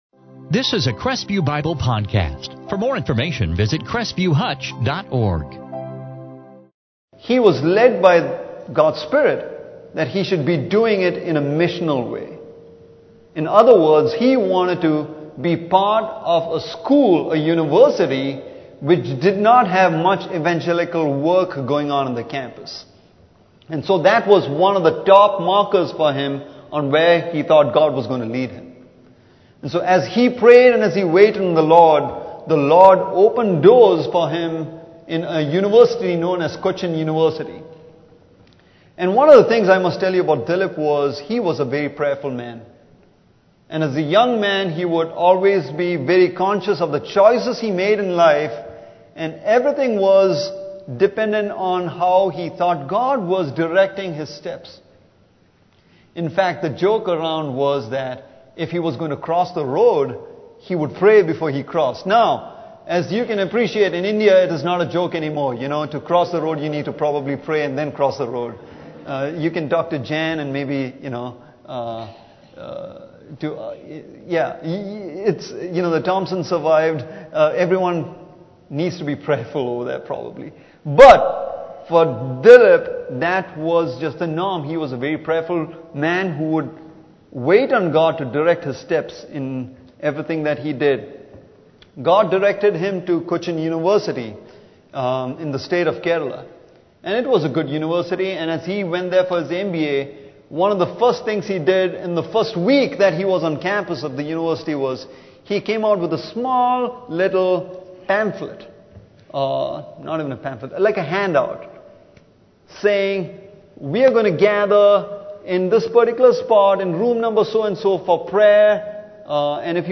2019 Stand Alone Sermons Acts Transcript In this sermon from Acts 16:6-40